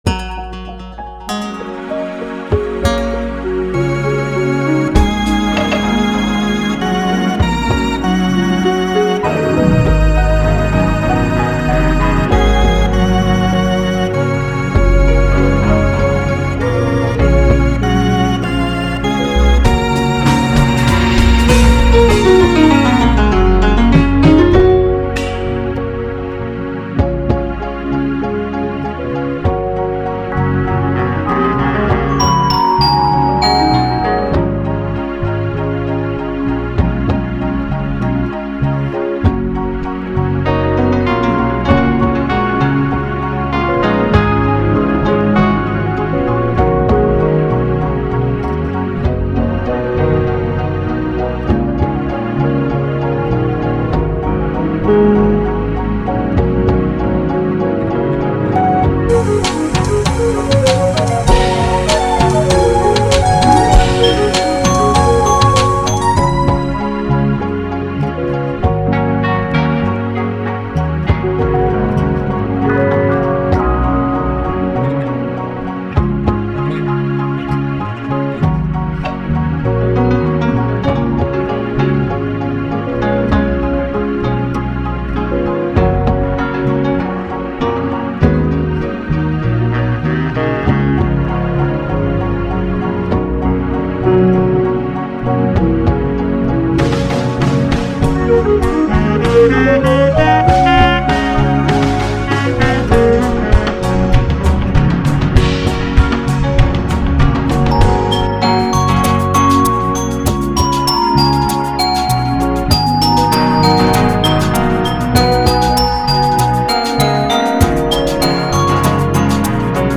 Beat
NỮ